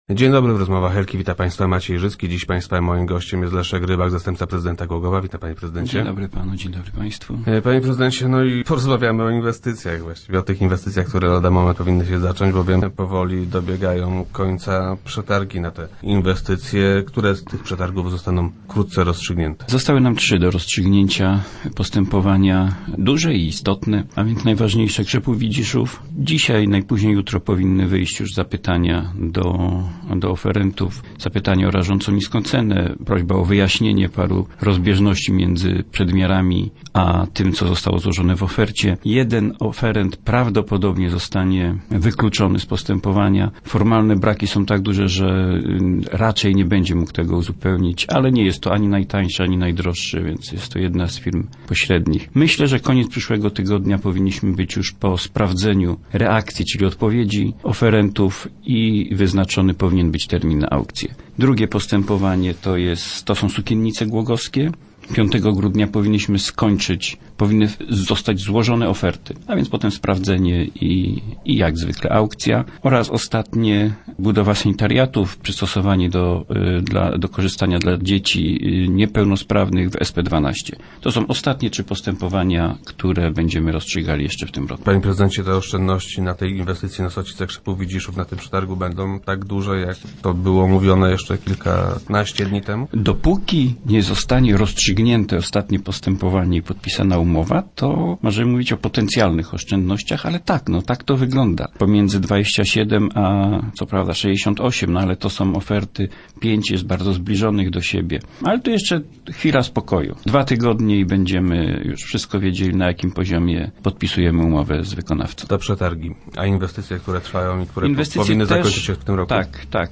- Zwłaszcza w tym przypadku musimy być ostrożni – twierdzi Leszek Rybak, zastępca prezydenta Głogowa.